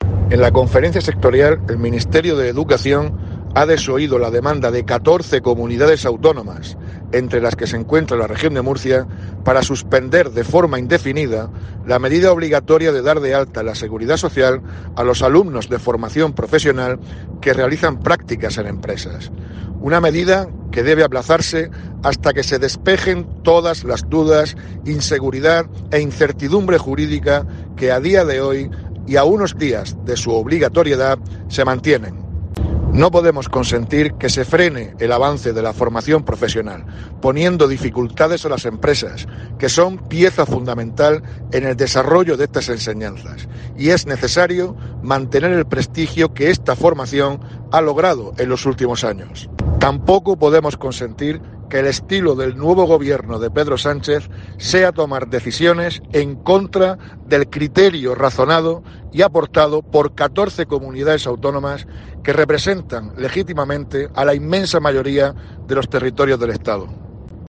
Víctor Marín, consejero de Educación